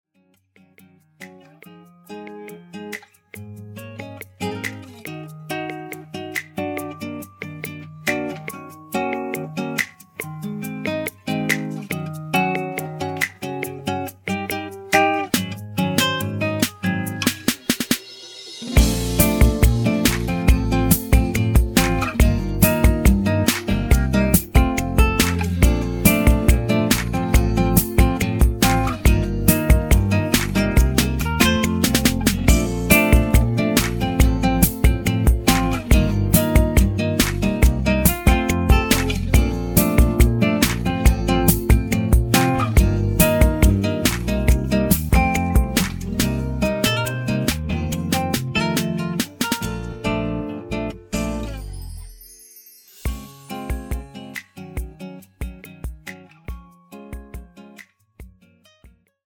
음정 원키 3:39
장르 가요 구분 Voice Cut